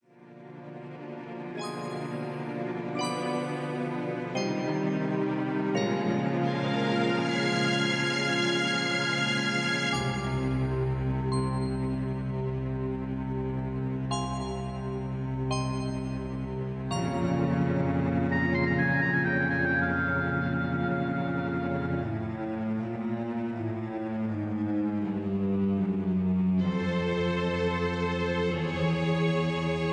Just Plain & Simply "GREAT MUSIC" (No Lyrics).
mp3 backing tracks